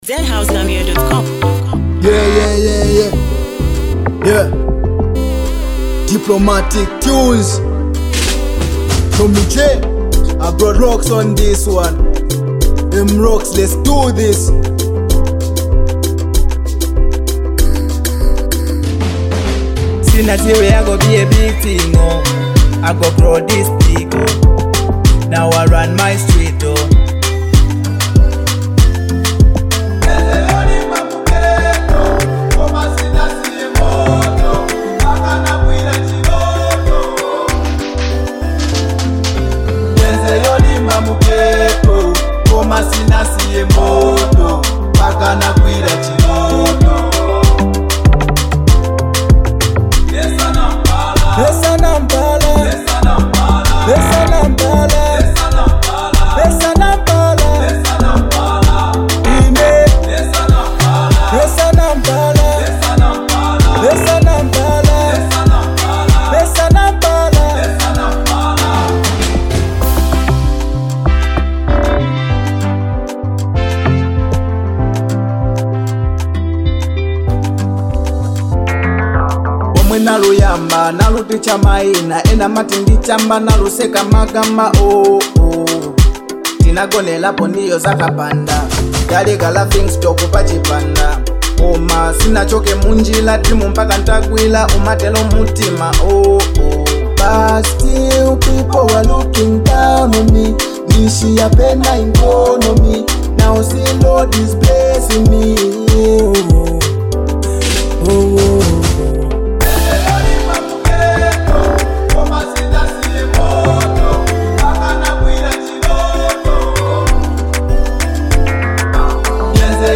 inspiring anthem
With uplifting lyrics and powerful beats
motivational track